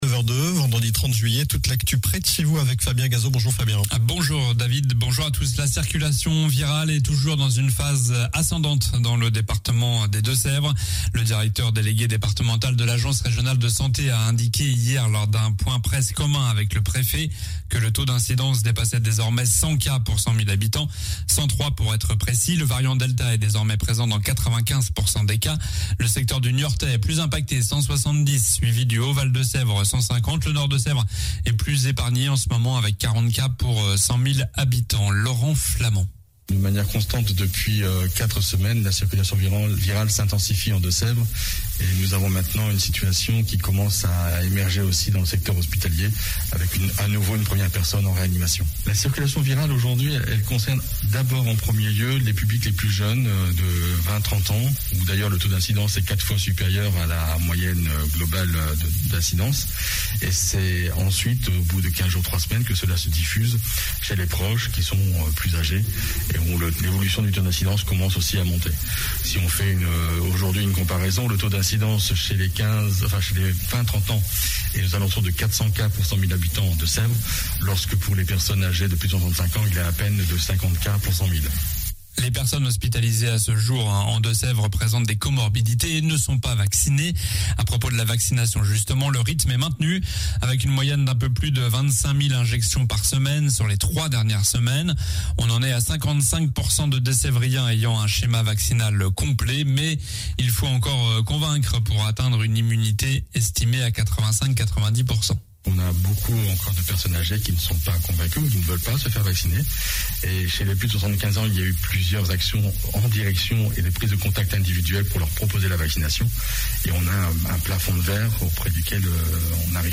Journal du vendredi 30 juillet (matin)